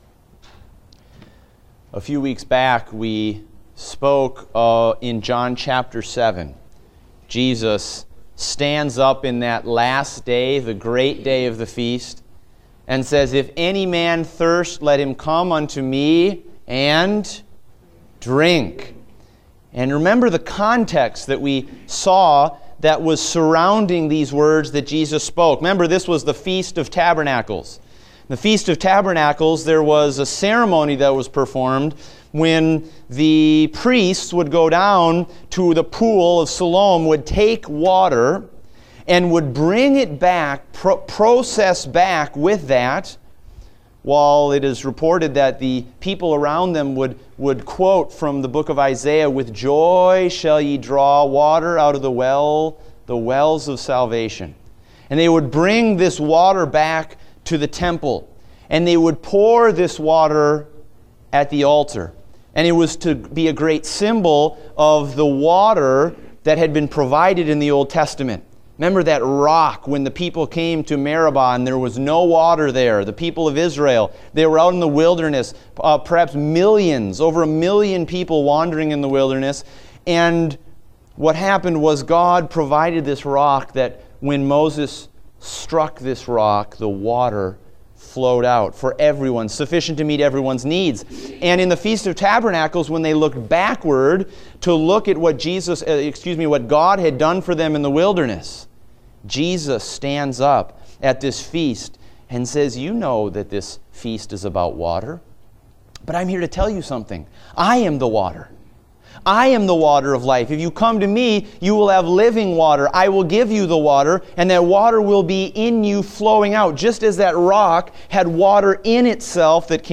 Date: October 9, 2016 (Adult Sunday School)